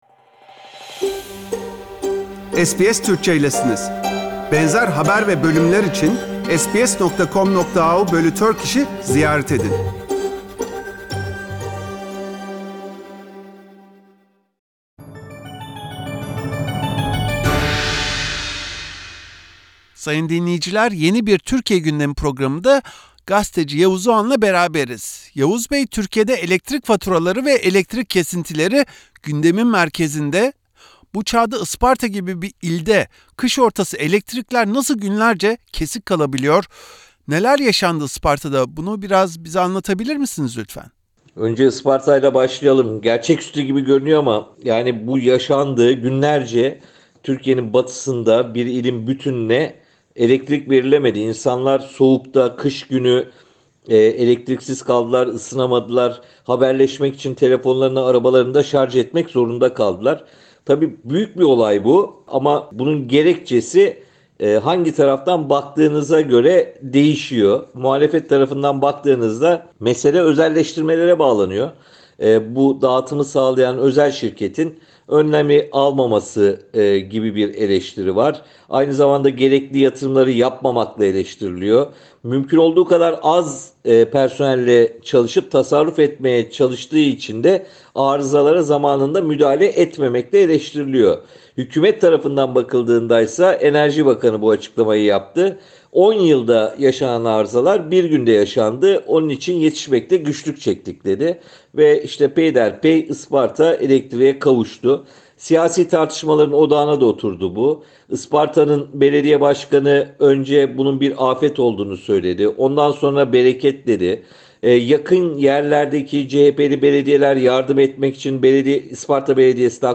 Isparta’da günler süren elektrik kesintilerinden bir anda iki ve hatta üç kat artan elektrik faturalarına kadar tüm güncel konuları gazeteci Yavuz Oğhan’la konuştuk.